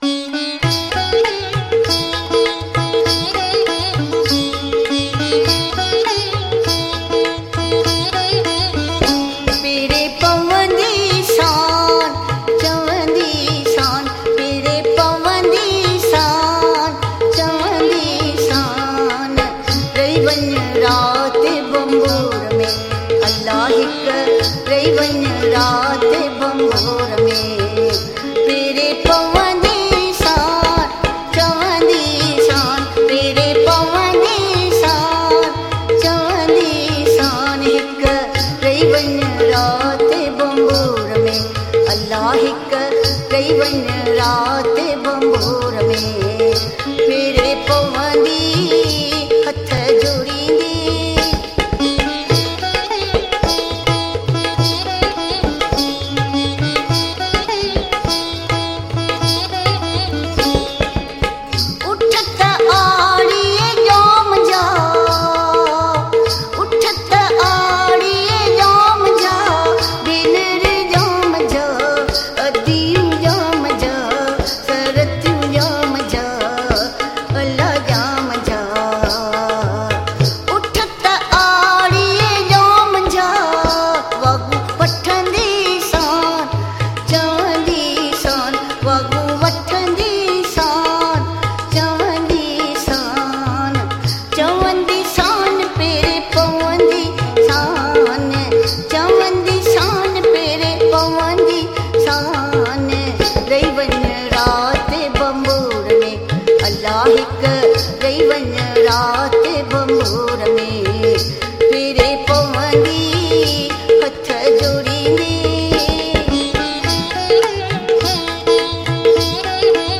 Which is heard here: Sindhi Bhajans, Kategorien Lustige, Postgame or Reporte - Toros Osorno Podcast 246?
Sindhi Bhajans